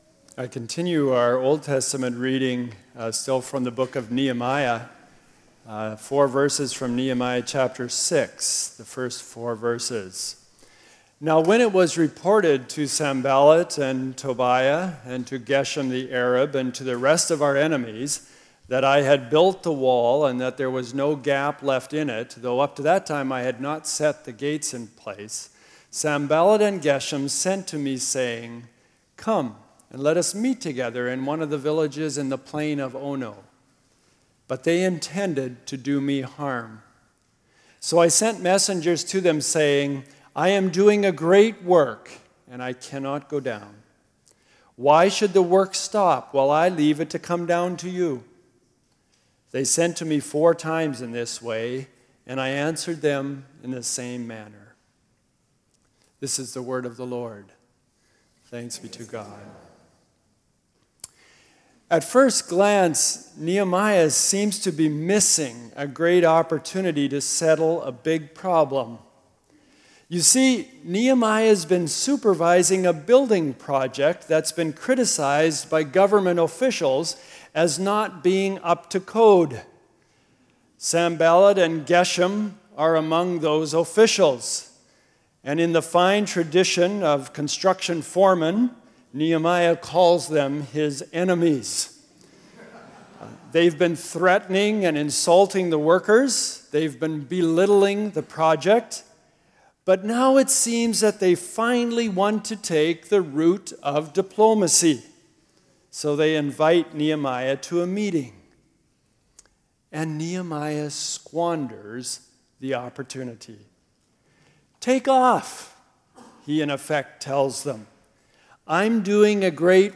2014 Sermons A Great Work Play Episode Pause Episode Mute/Unmute Episode Rewind 10 Seconds 1x Fast Forward 30 seconds 00:00 / Subscribe Share RSS Feed Share Link Embed Download file | Play in new window